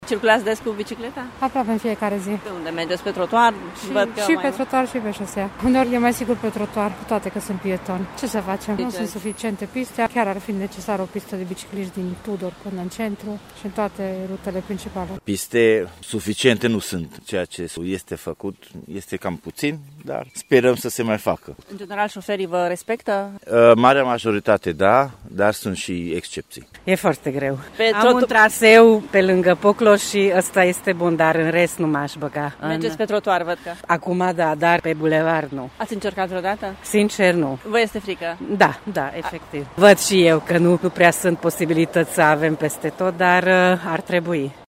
Bicicliștii sunt conștienți că nu există posibilitatea realizării pistelor în tot orașul, din cauza străzilor înguste: